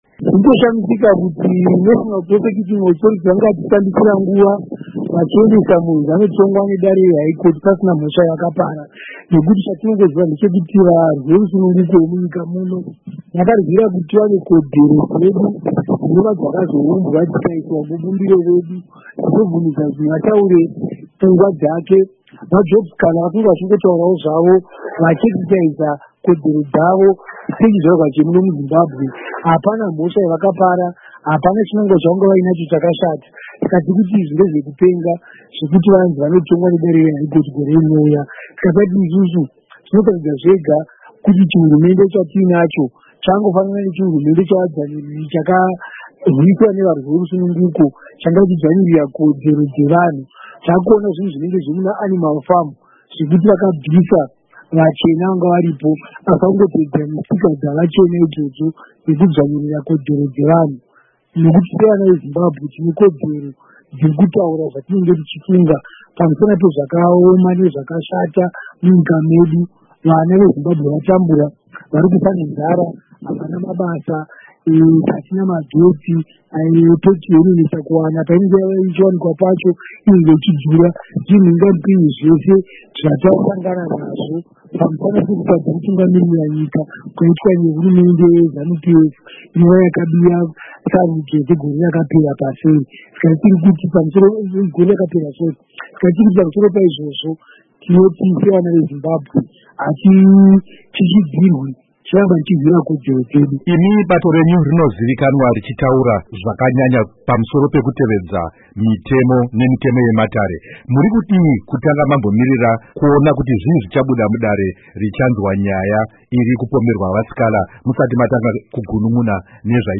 Hurukuro naVaInnocent Gonese